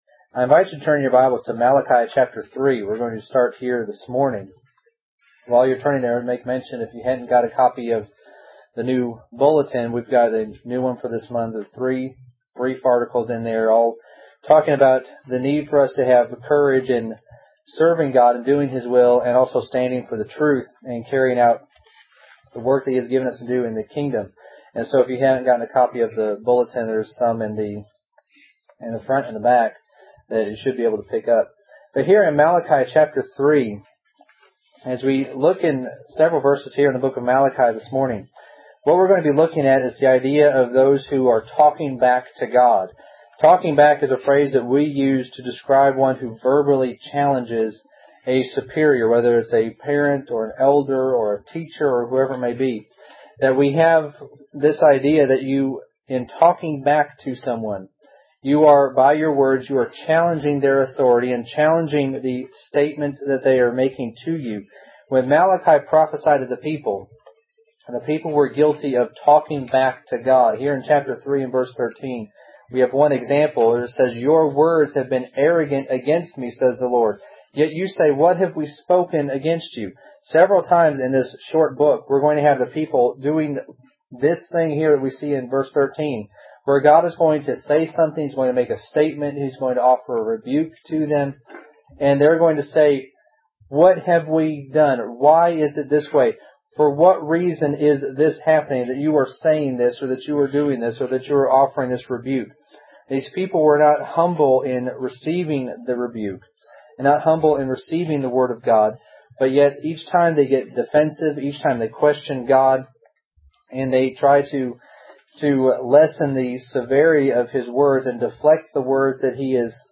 When Malachi prophesied, the people were guilty of talking back to God (ex: Malachi 3:13). Several times in this short book, the people openly challenged God’s statements/rebukes. In this lesson, we will briefly consider the examples of the people talking back to God so that we might learn not to do the same.